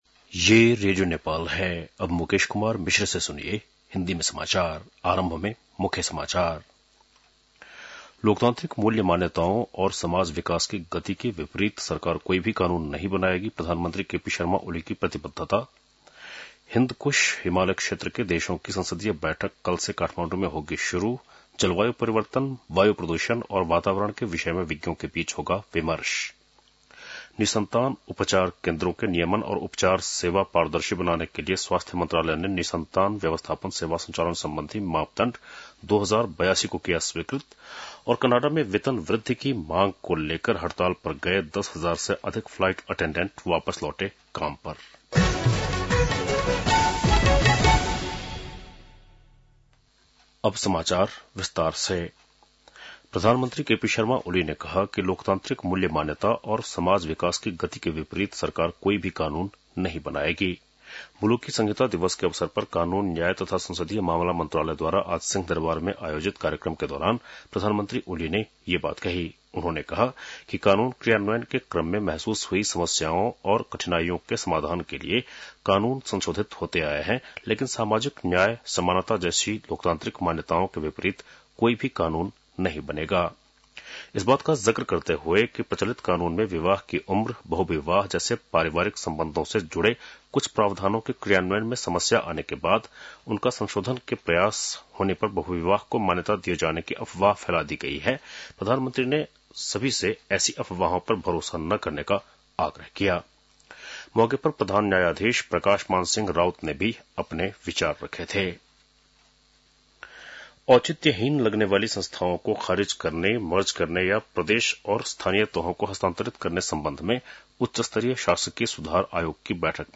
बेलुकी १० बजेको हिन्दी समाचार : १ भदौ , २०८२
10-pm-hindi-news-1-1.mp3